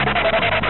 skid.mp3